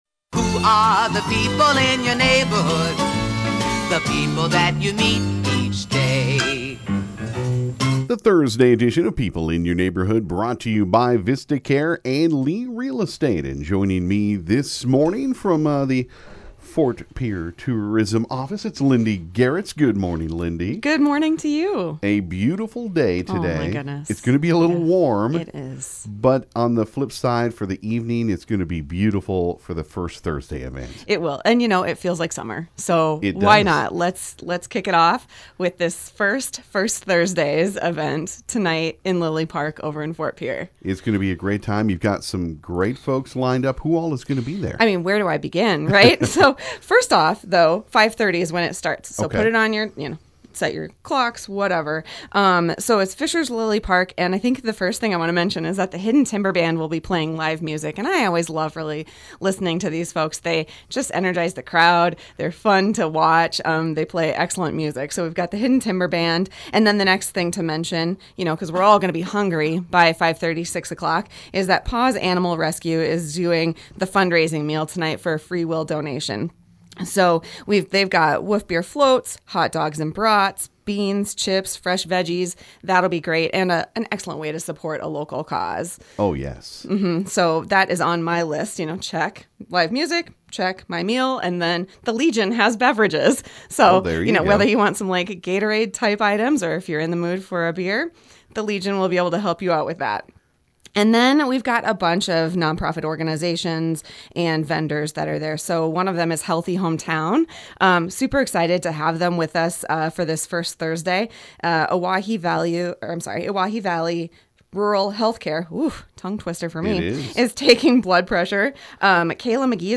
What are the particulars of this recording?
Pierre Tourism Office stopped into the KGFX studio this morning to talk about the kickoff First Thursday Event tonight in Fischers Lilly Park. There will be music, food, vendors and more for everyone to enjoy.